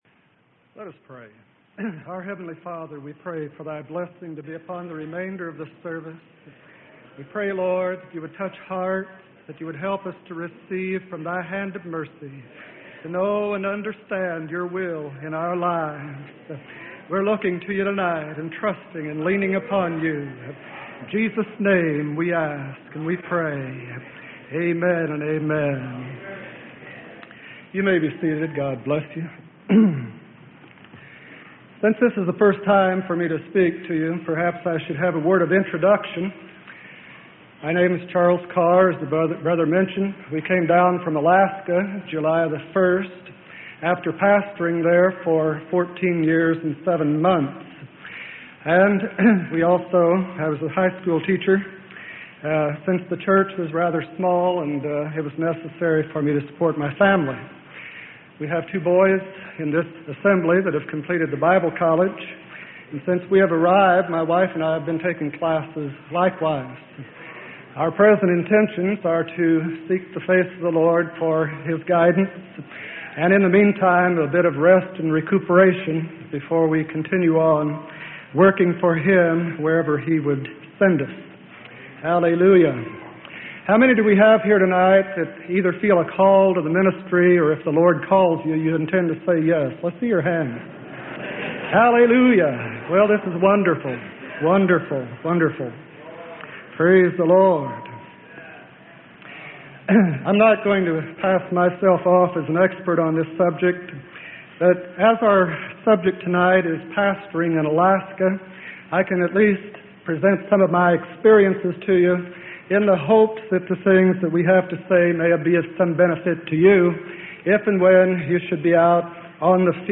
Sermon: Personal Experiences In The Ministry.